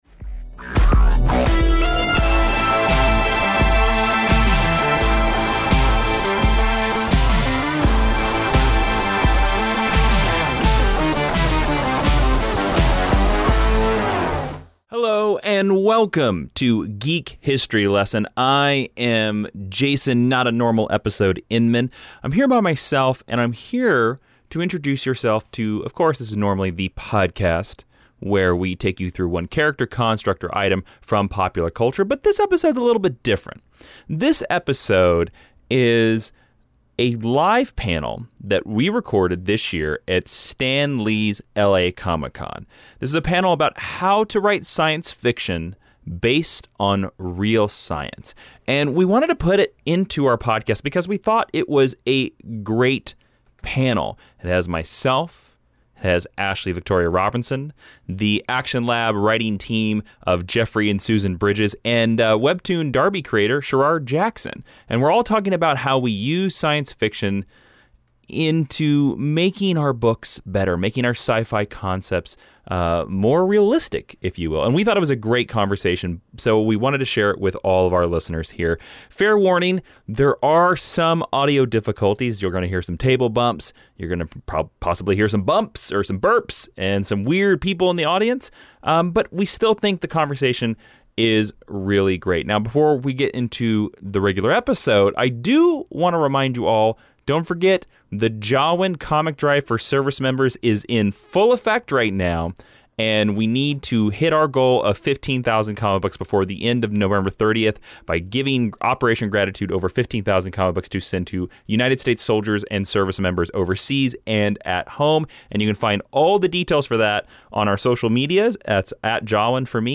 Downloads Download How to Write Sci Fi Based on Real Science (Live Panel).mp3 Download How to Write Sci Fi Based on Real Science (Live Panel).mp3 Content SUPER EARLY EPISODE RELEASE!!!!